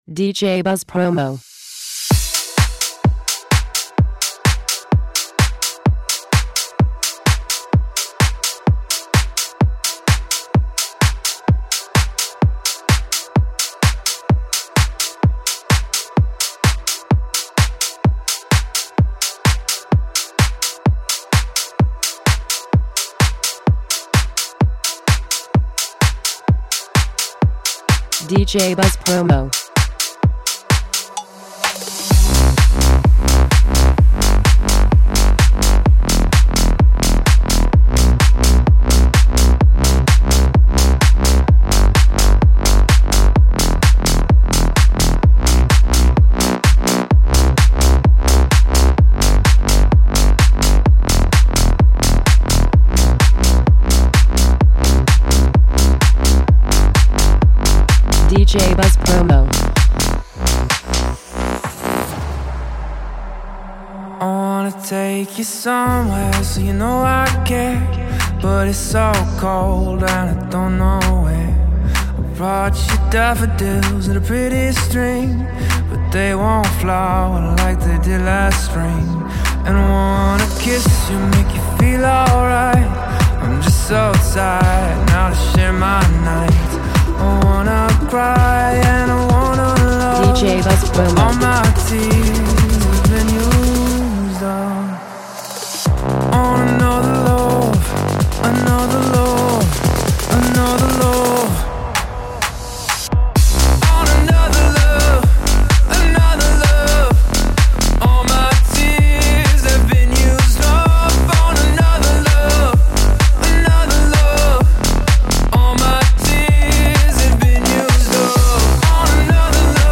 Extended Mix